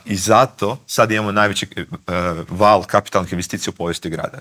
On je u prvom krugu lokalnih izbora osvojio 47,59 posto glasova Zagrepčana, a u Intervjuu tjedna Media servisa istaknuo je da je najvažnija većina u zagrebačkoj Gradskoj skupštini: